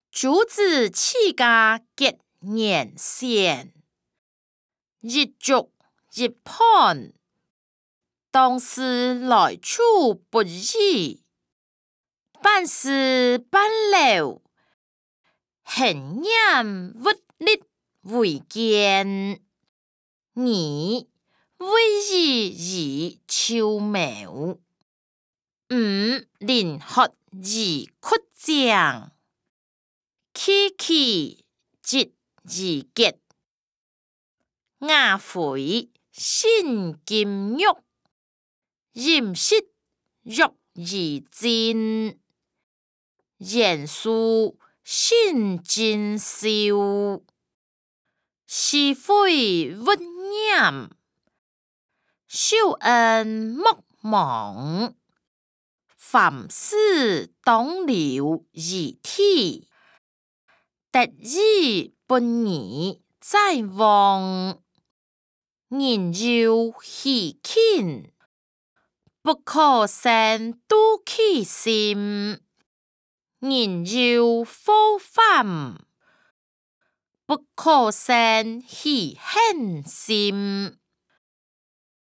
歷代散文-朱子治家格言選音檔(大埔腔)